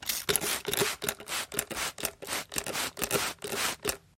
Звуки аэрозоля
Спрей для свежести